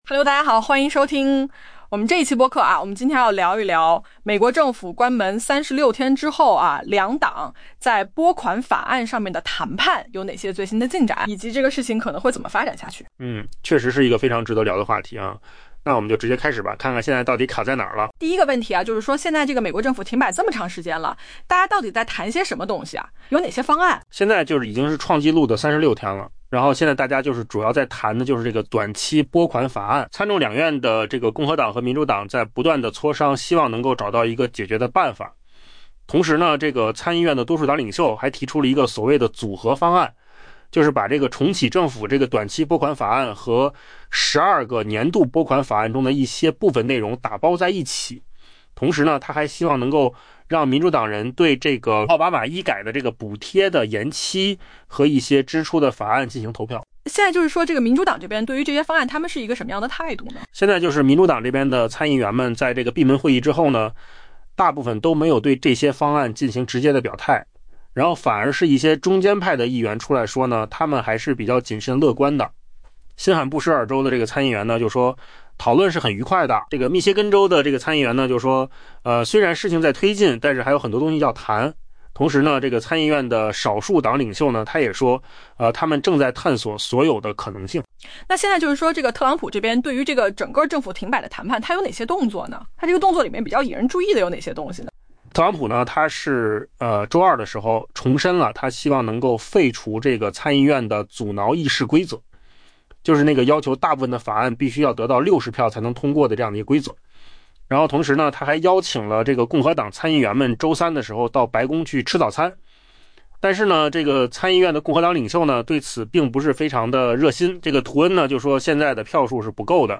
AI 播客：换个方式听新闻 下载 mp3 音频由扣子空间生成 美国共和党和民主党参议员对达成跨党派协议结束政府停摆表示乐观，同时也对议员们能否快速打破僵局发出谨慎信号。